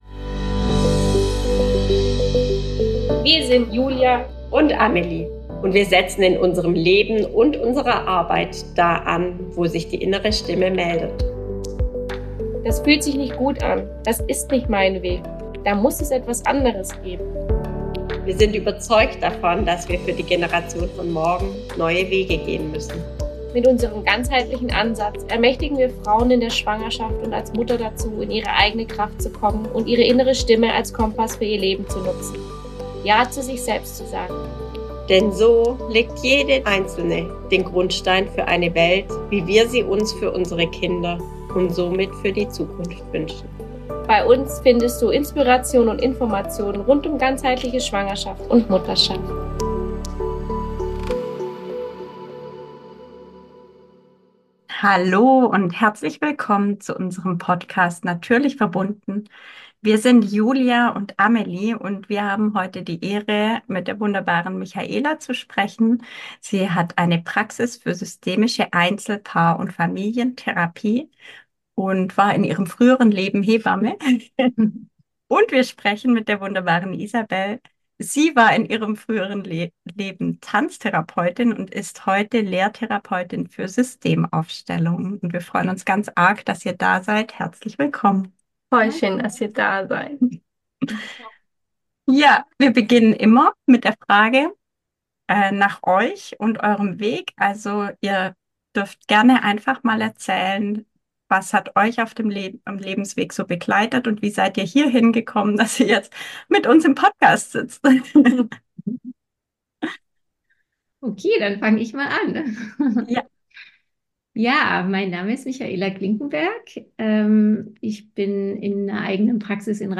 Sie erklären, was ein System ist und wie systemische Therapie bzw. Beratung auf den Menschen blickt. Ein wunderbar rundes Gespräch über die Veränderungen im Leben, was uns ins Wanken bringt und was helfen kann um in die Balance zurück zu finden.